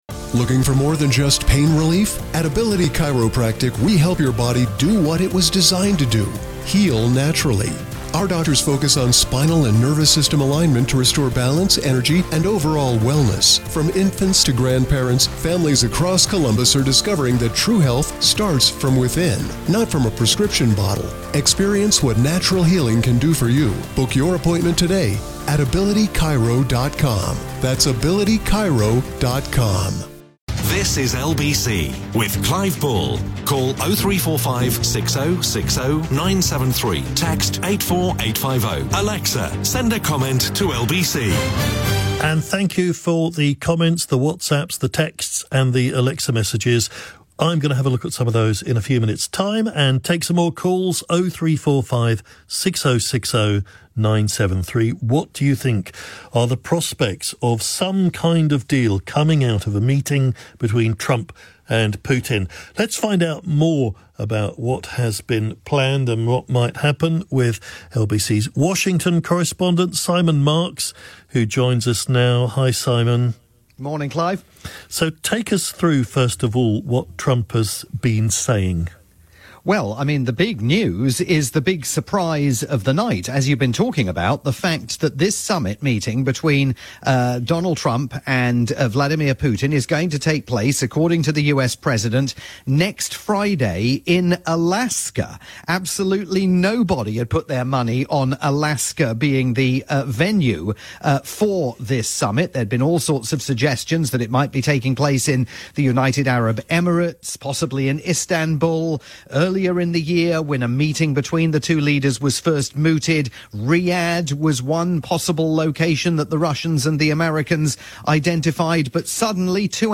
live update